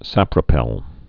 (săprə-pĕl)